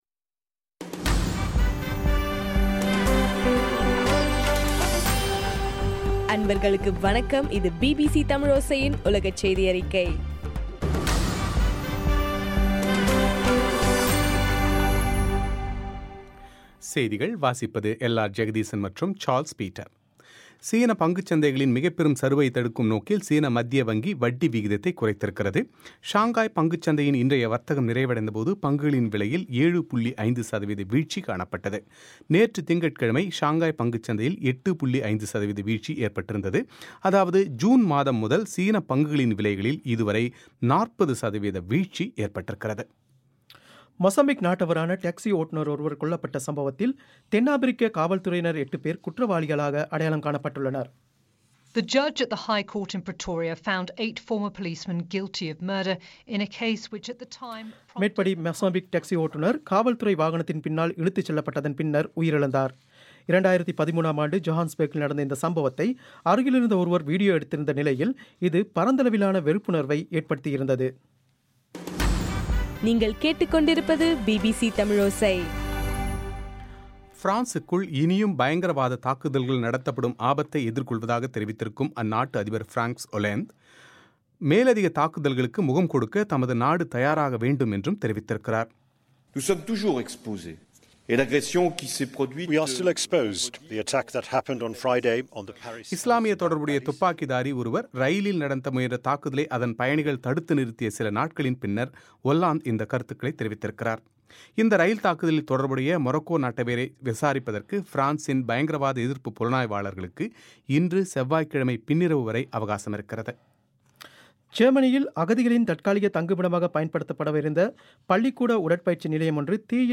ஆகஸ்ட் 25 பிபிசியின் உலகச் செய்திகள்